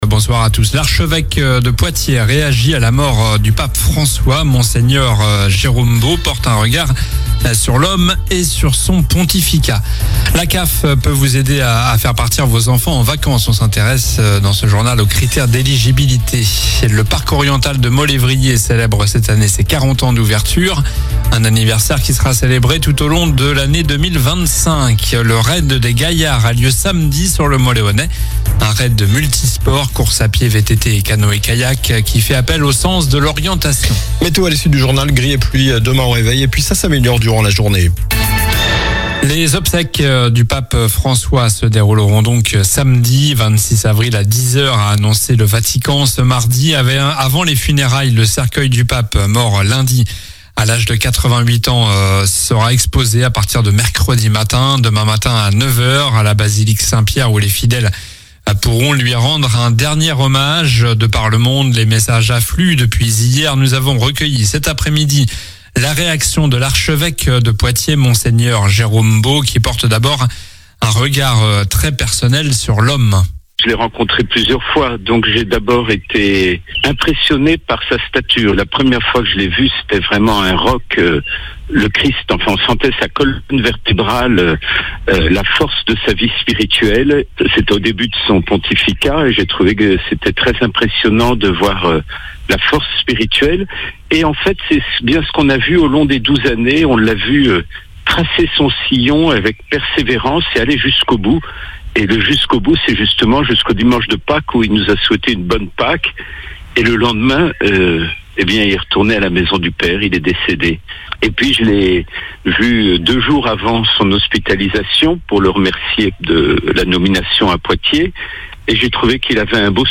Journal du mardi 22 avril (soir)